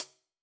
Index of /musicradar/Hi Hats/Sabian B8
CYCdh_Sab_ClHat-07.wav